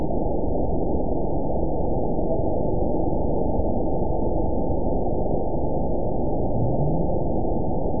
event 922752 date 03/25/25 time 18:33:25 GMT (2 months, 3 weeks ago) score 9.63 location TSS-AB01 detected by nrw target species NRW annotations +NRW Spectrogram: Frequency (kHz) vs. Time (s) audio not available .wav